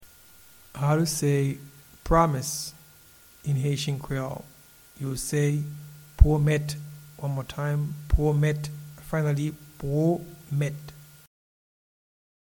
Promise-in-Haitian-Creole-Pwomet.mp3